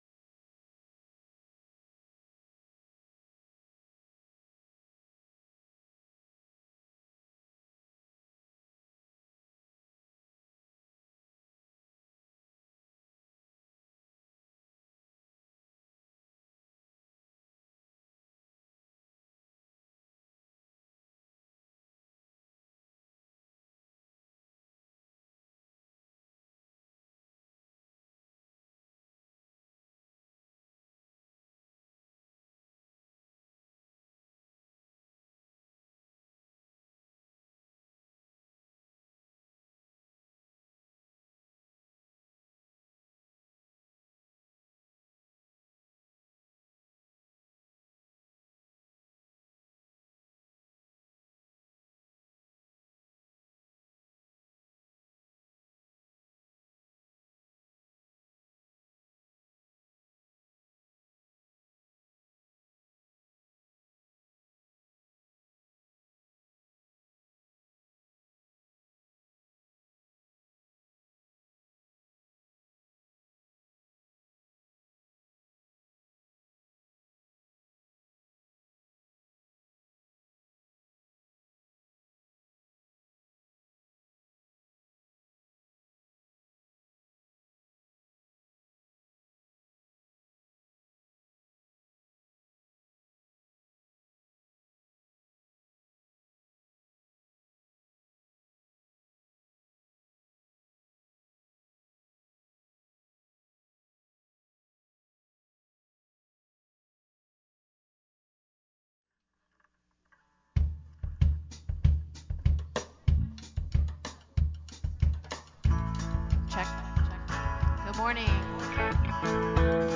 Praise Worship
Prayer Requests and Praises